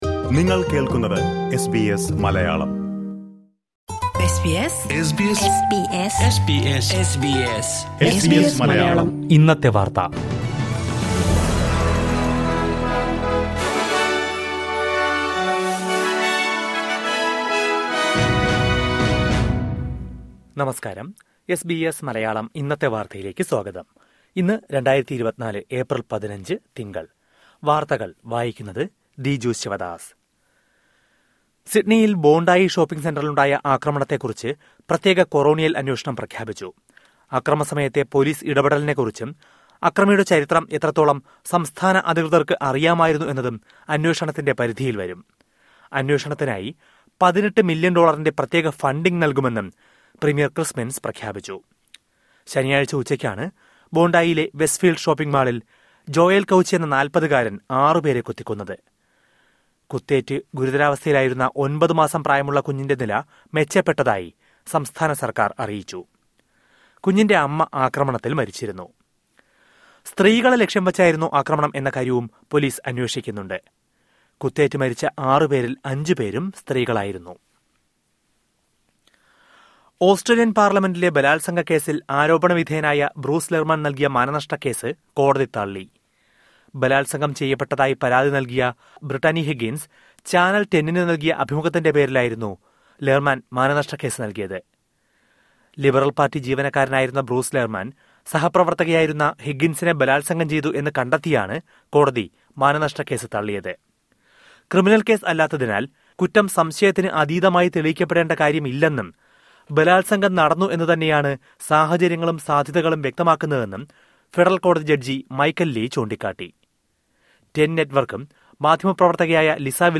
2024 ഏപ്രില്‍ 15ലെ ഓസ്‌ട്രേലിയയിലെ ഏറ്റവും പ്രധാന വാര്‍ത്തകള്‍ കേള്‍ക്കാം...